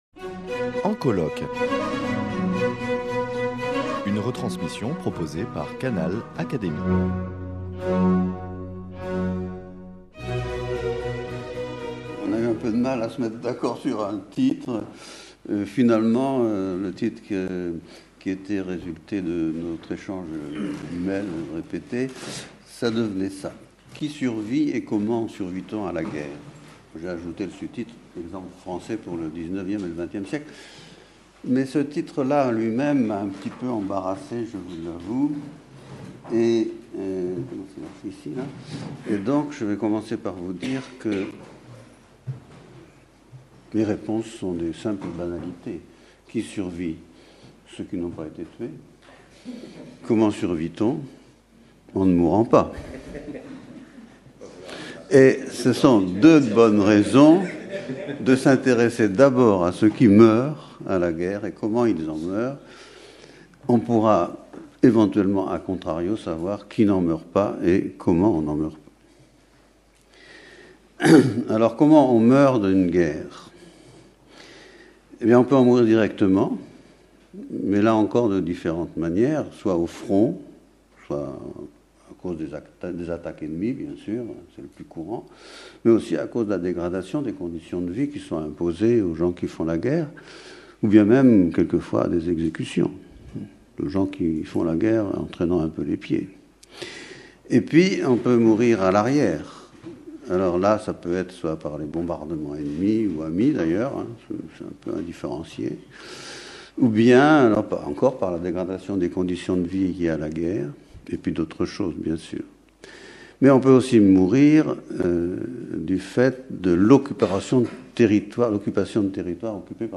prononcée le 9 décembre 2015 lors des journées d’étude « Guerre et santé »